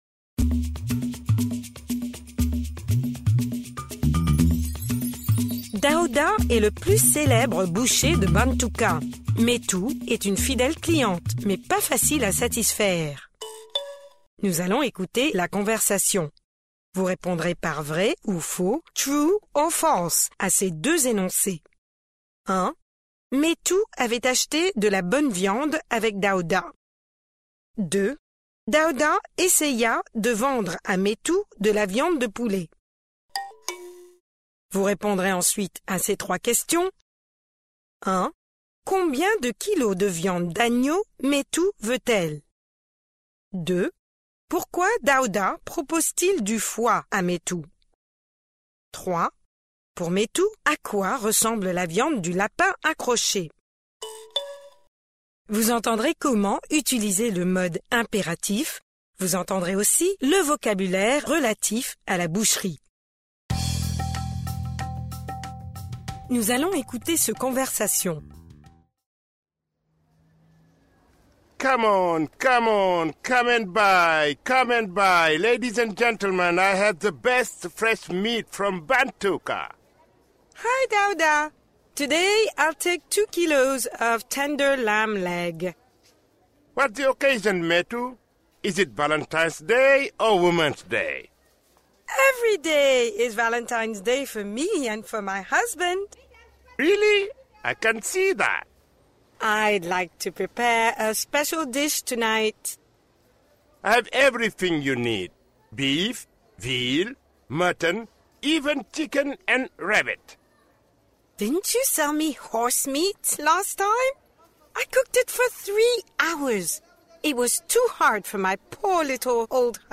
Pre-listening: Nous allons écouter la conversation.